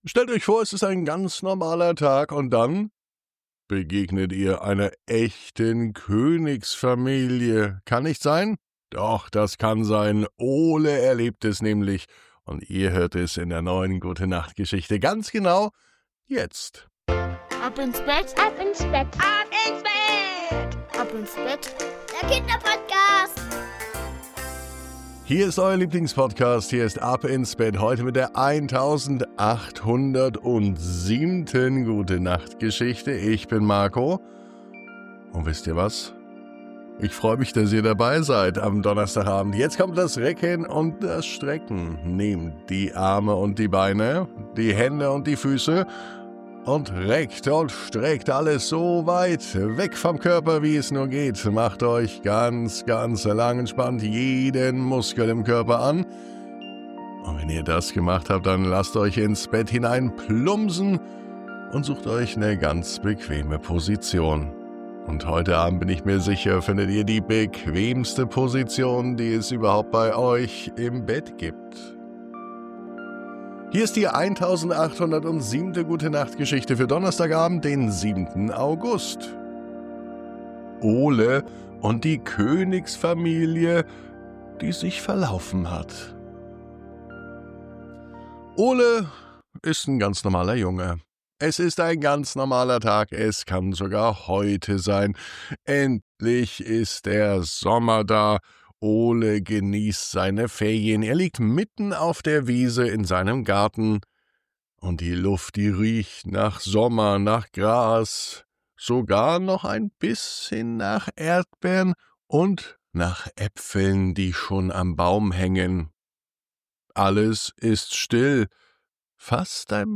Eine magische Gute-Nacht-Geschichte zum Staunen, Träumen und Einschlafen. Wie immer liebevoll erzählt – für kleine Königskinder und große Träumer.